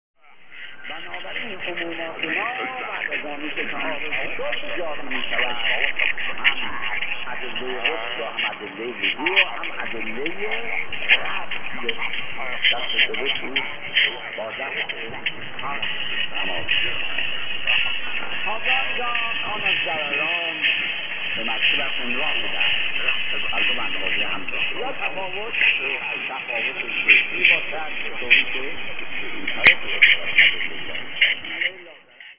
1071 KHz with man in Farsi.